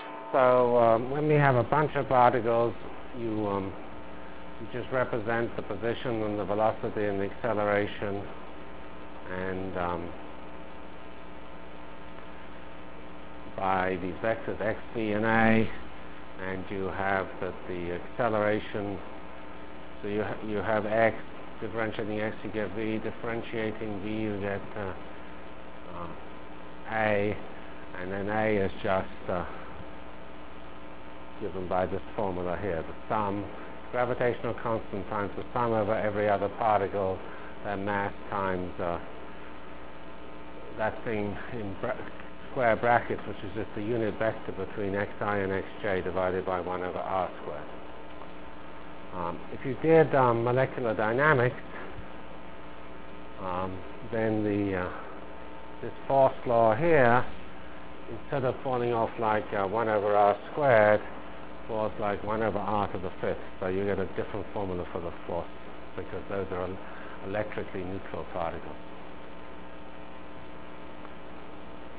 Delivered Lectures